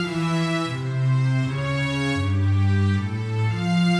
Index of /musicradar/80s-heat-samples/120bpm
AM_80sOrch_120-E.wav